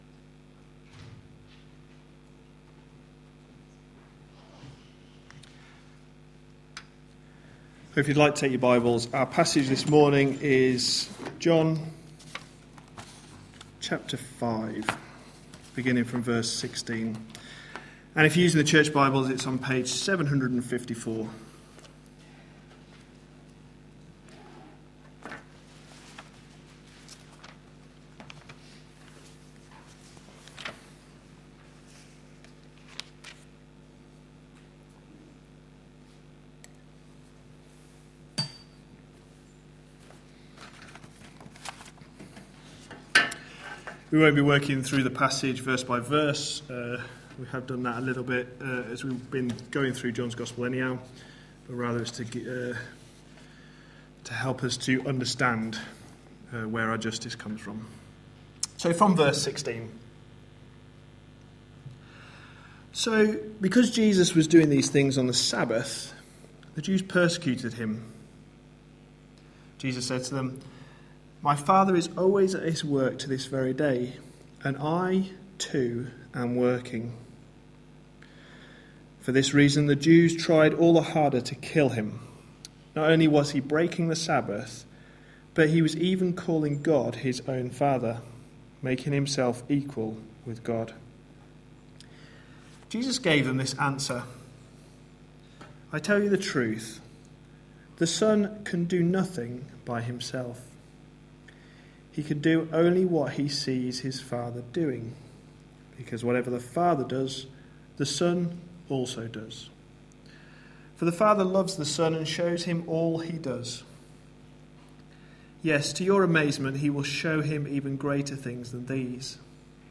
A sermon preached on 5th January, 2014, as part of our The gospel is the reason series.